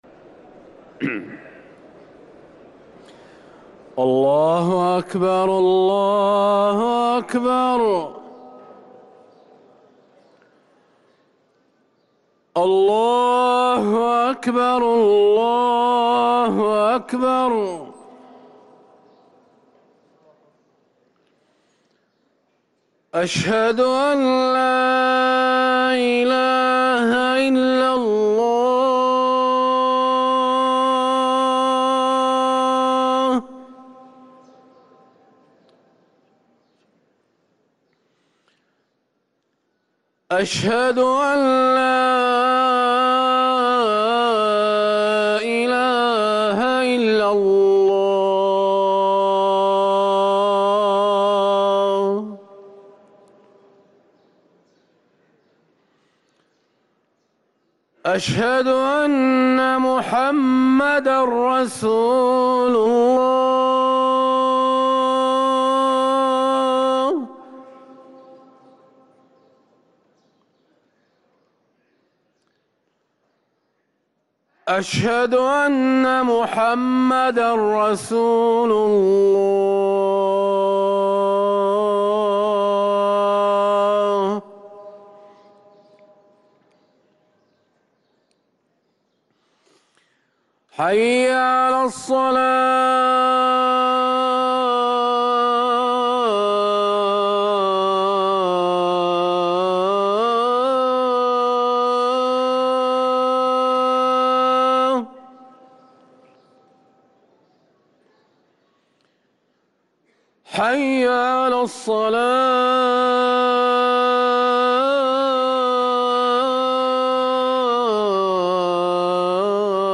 أذان الظهر
ركن الأذان